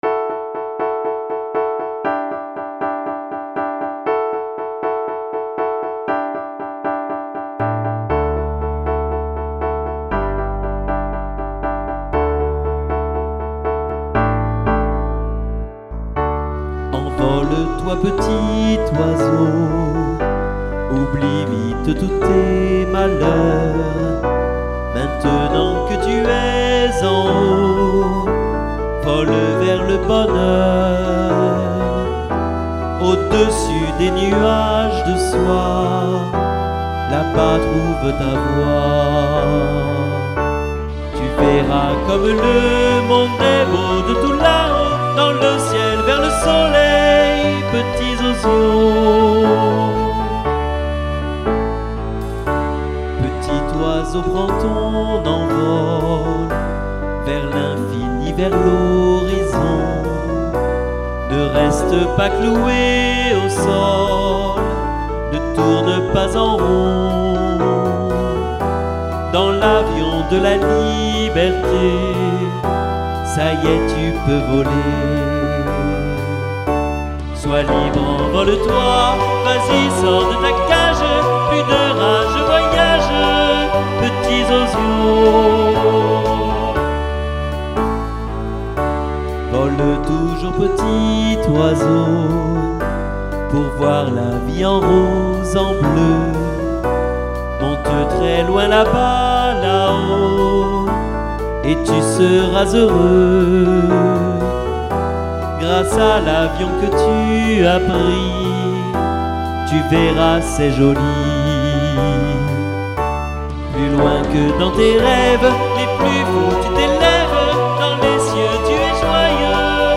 Documents à écouter ou télécharger : Téléchargez l'historique du Projet et les Paroles Écoutez la version chantée avec voix témoin Entraînez-vous à chanter sur le PlayBack pour le samedi 18 mai !